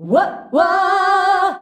UAH-UAAH G.wav